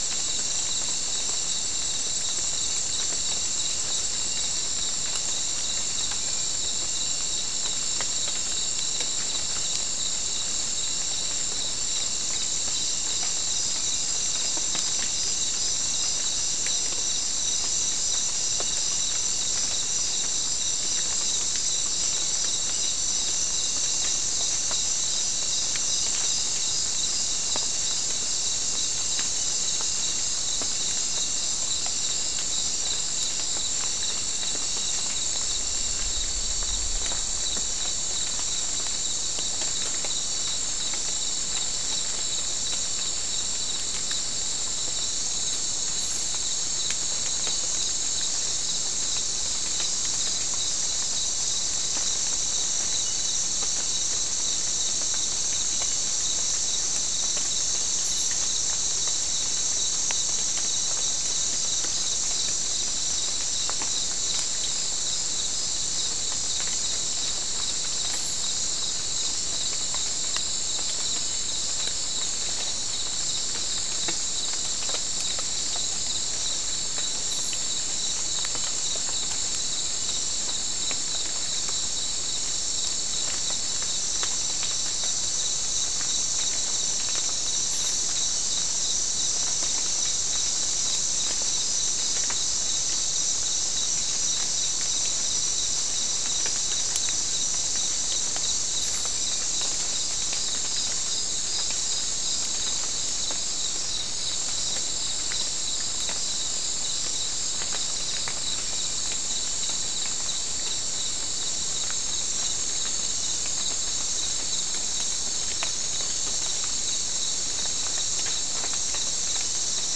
Non-specimen recording: Soundscape Recording Location: South America: Guyana: Sandstone: 2
Recorder: SM3